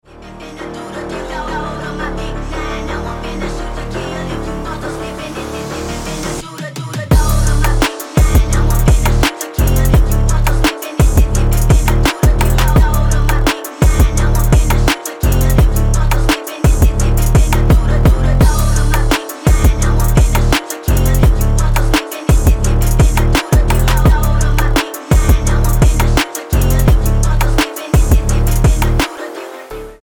Phonk Music